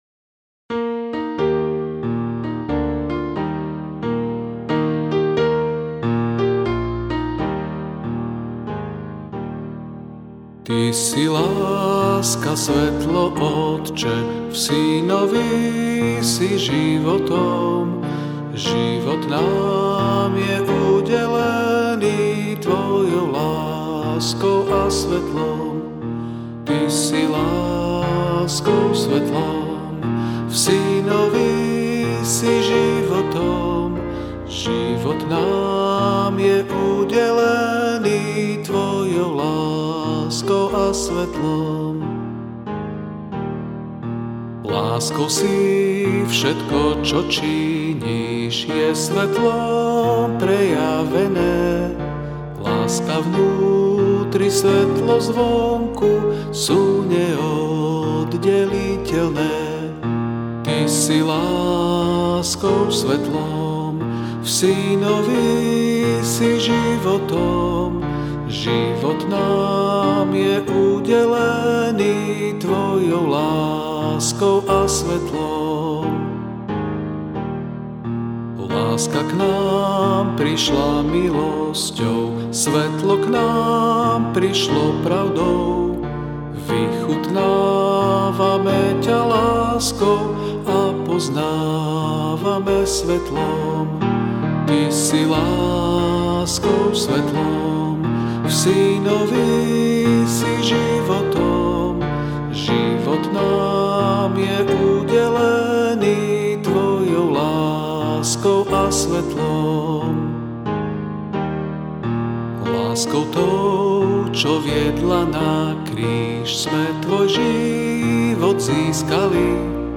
F Major
8.7.8.7. with chorus.